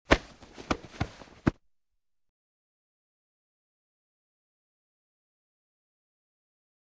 Grabs With Cloth Movement